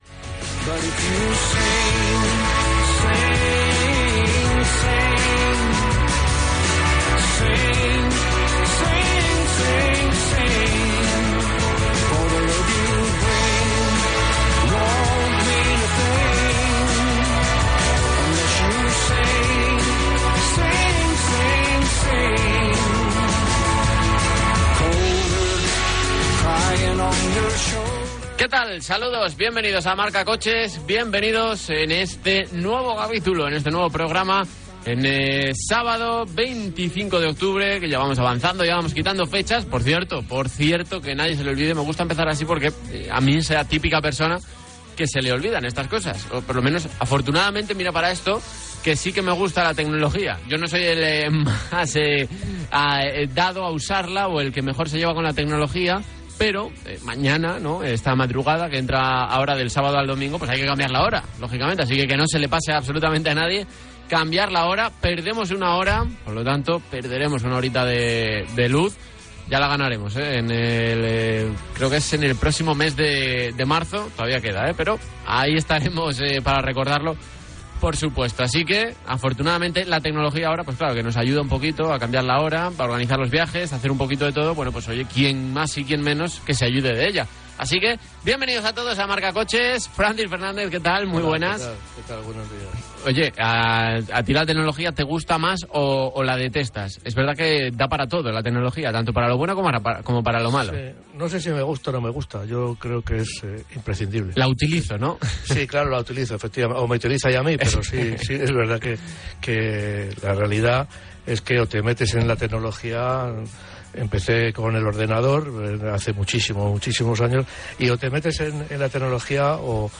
Sintonia, data, presentació amb avís del canvi d'hora i comentari, el consum dels vehicles